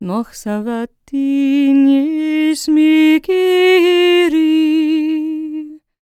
L  MOURN A02.wav